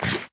eat2.ogg